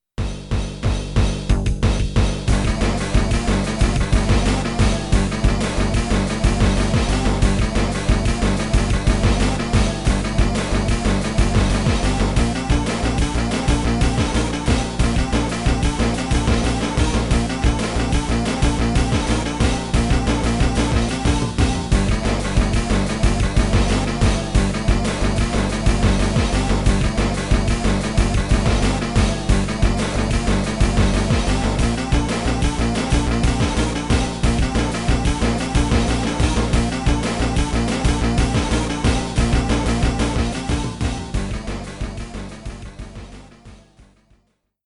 Boss theme